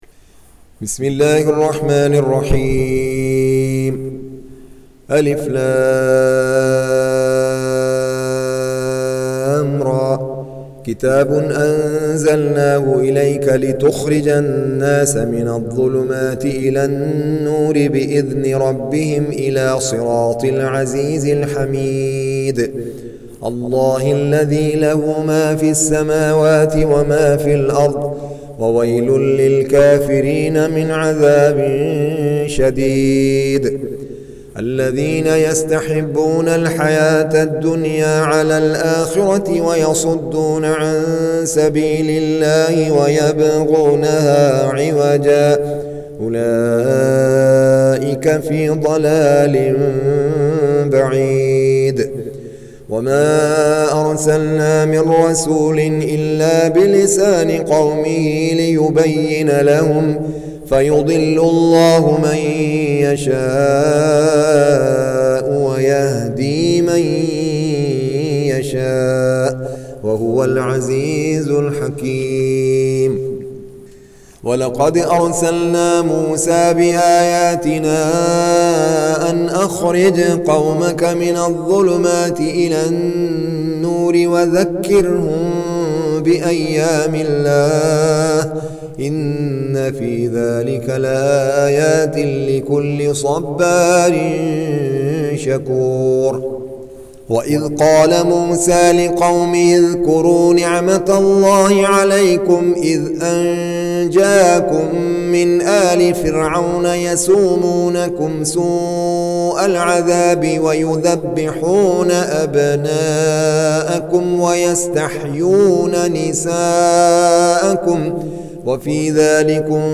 Surah Repeating تكرار السورة Download Surah حمّل السورة Reciting Murattalah Audio for 14. Surah Ibrah�m سورة إبراهيم N.B *Surah Includes Al-Basmalah Reciters Sequents تتابع التلاوات Reciters Repeats تكرار التلاوات